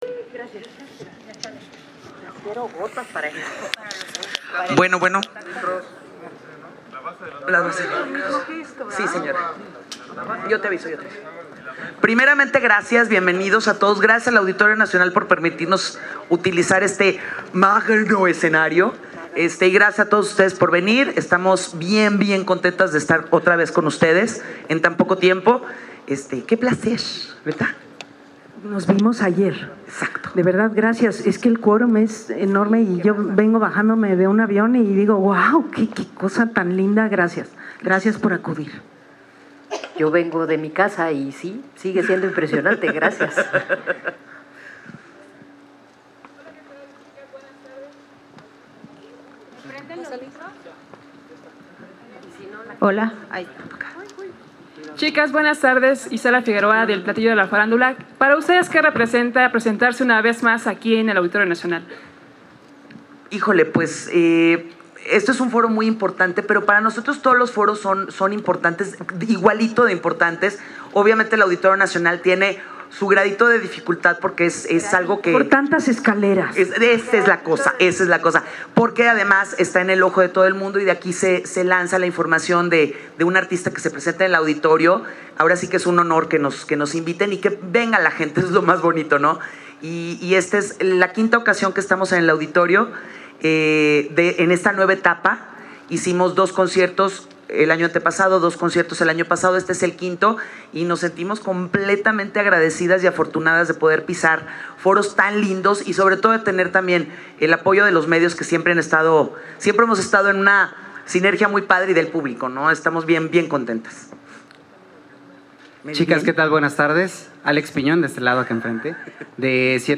No te pierdas aquí la chispa del trio durante la conferencia de prensa: 160426_0521